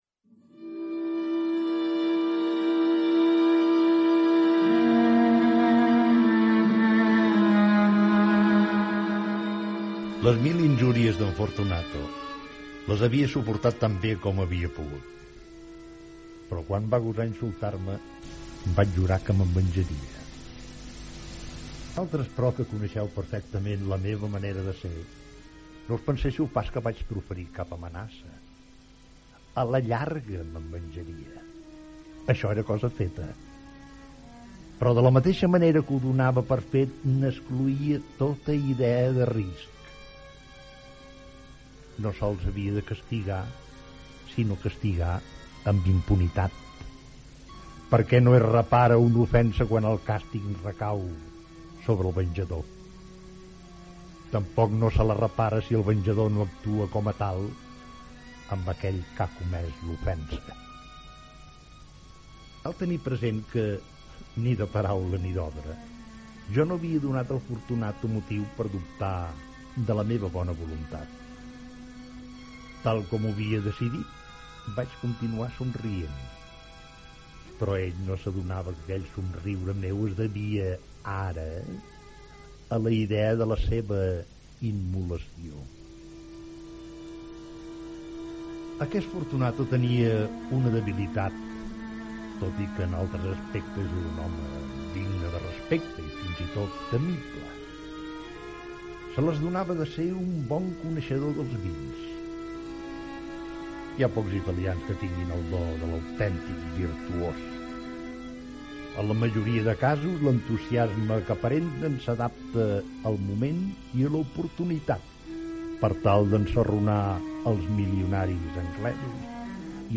Versió radiofònica de l'obra "El Barril d'Amontillat" d'Edgar Allan Poe Gènere radiofònic Ficció
Aquesta ficció sonora va ser enregistrada, a finals dels 80, als estudis de Ràdio Berga i no es va emetre mai.